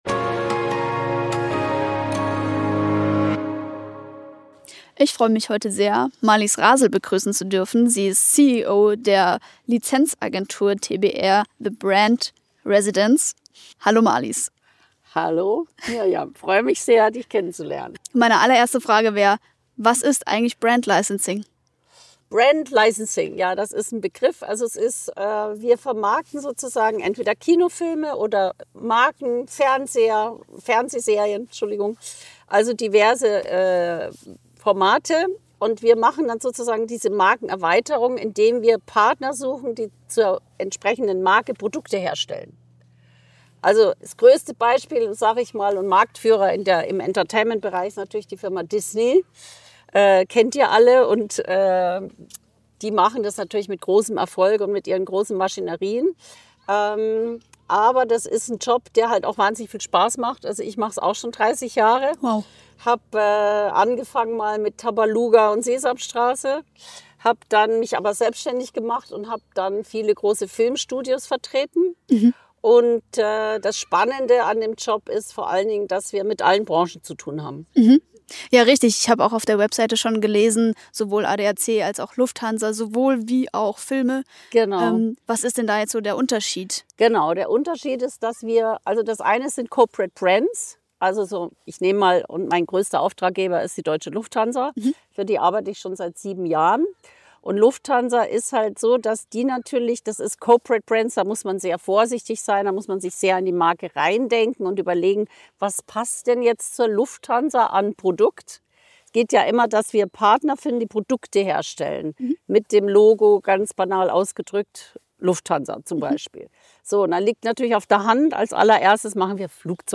Zusammenfassung In diesem Gespräch